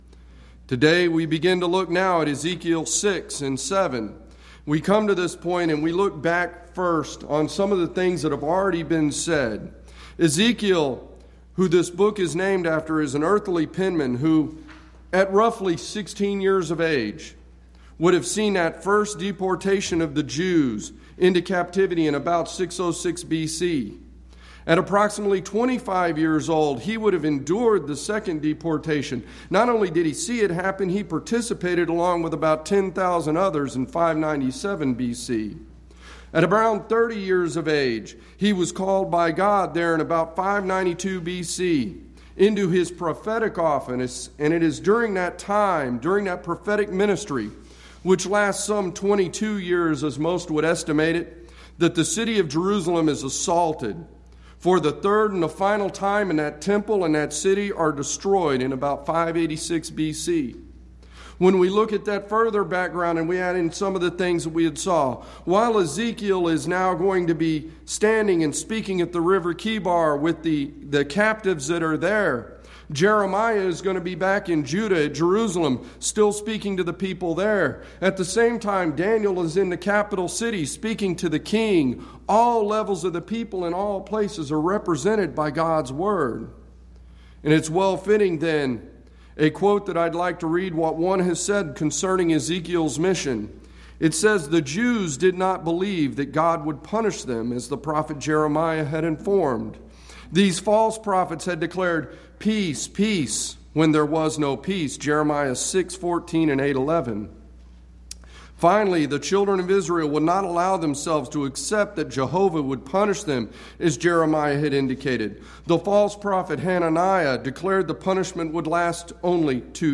Event: 10th Annual Schertz Lectures Theme/Title: Studies in Ezekiel